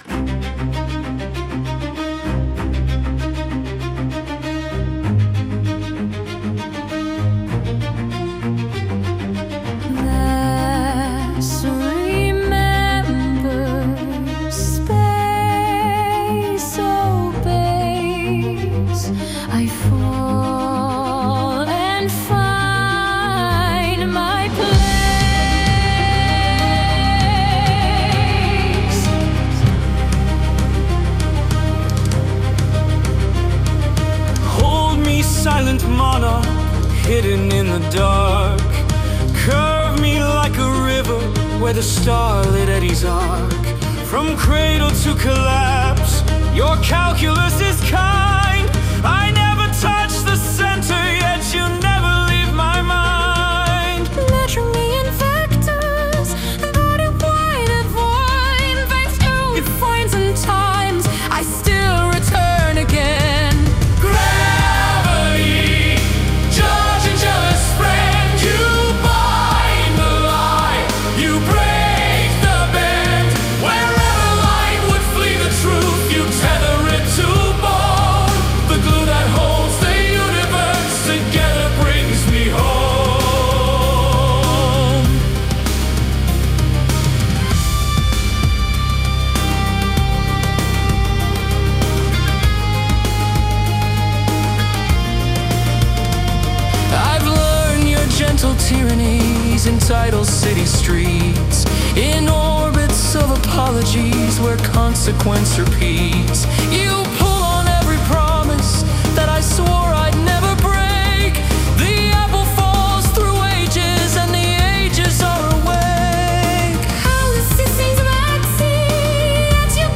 Space Metal